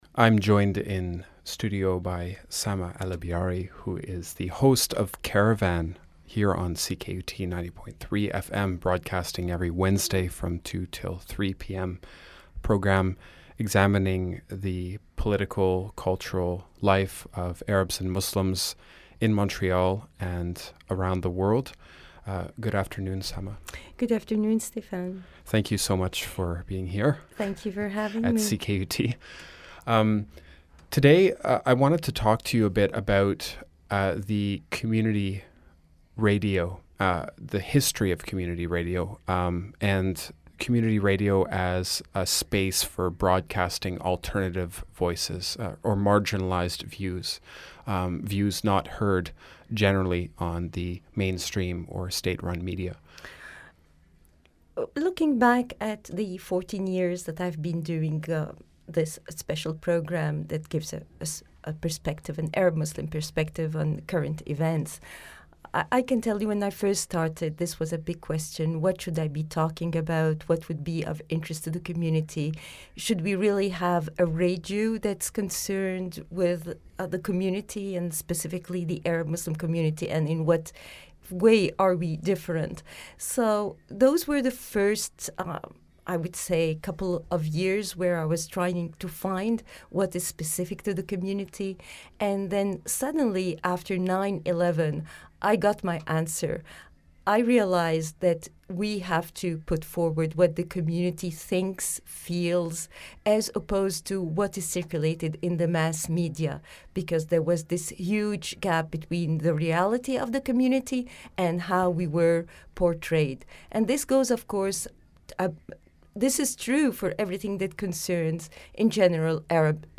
CKUT Radio: Reflections on political history of community radio discussion